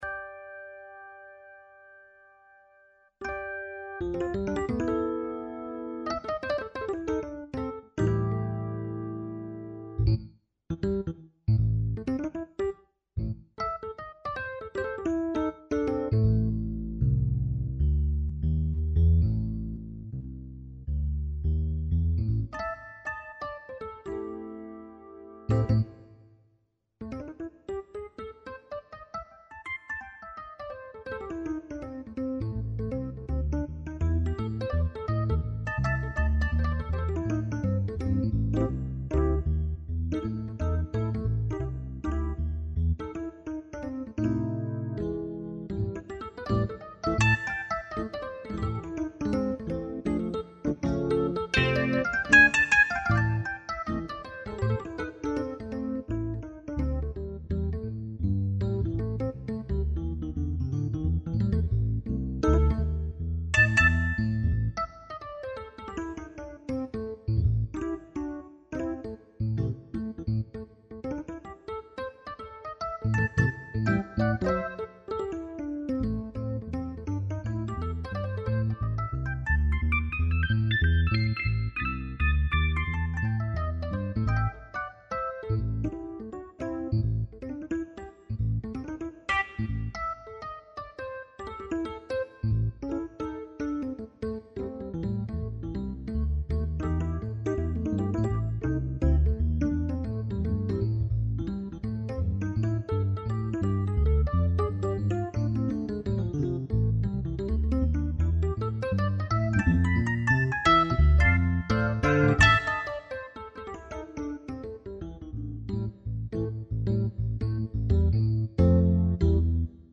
Blues
tsss Schönes Wetter – nix wia naus – intuitive Echtzeit-Improvisation 2006